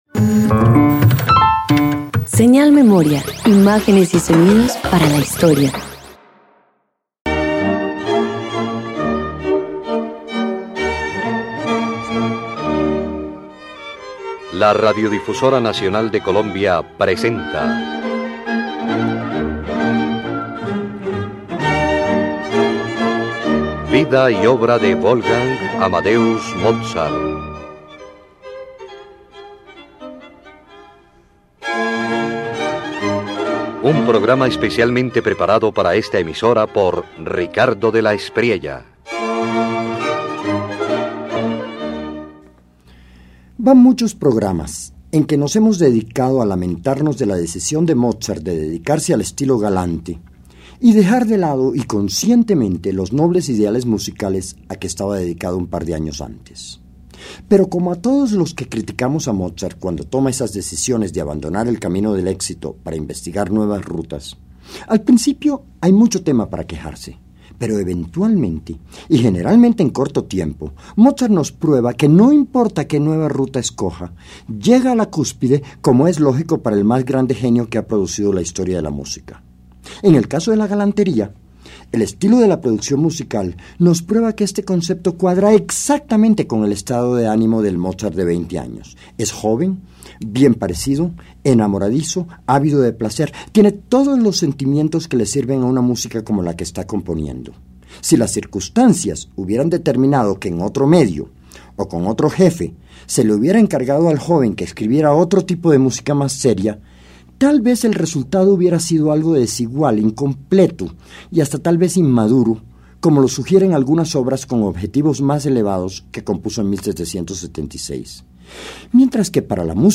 En 1776, Mozart compone el Concierto n.º 7 en fa mayor K.242 para tres pianos, una obra galante y luminosa que más tarde adapta para dos pianos. Su elegancia juvenil refleja la plenitud creativa y romántica del compositor a los 20 años.